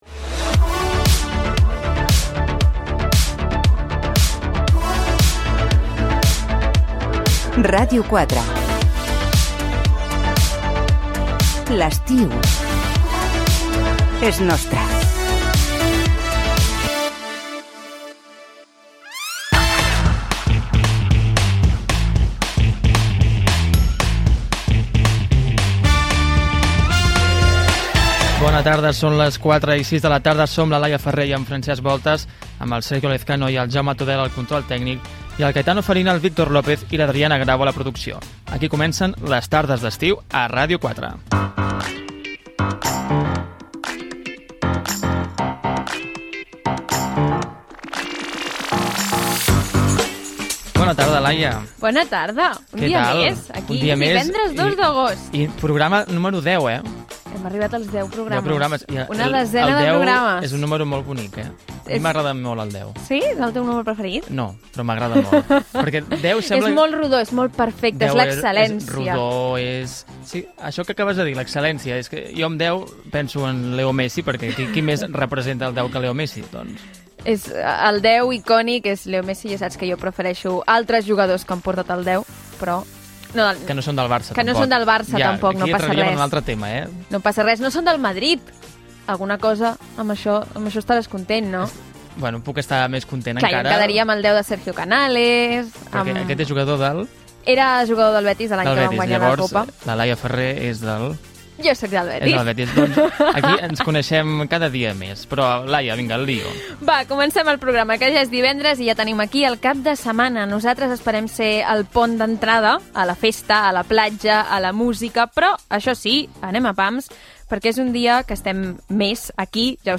Indicatiu d'estiu de la ràdio, equip del programa, diàleg sobre el número 10, reflexió sobre l'estiu, tema musical, sumari, els Jocs Olímpics de París, el dia internacional de la cervesa
Entreteniment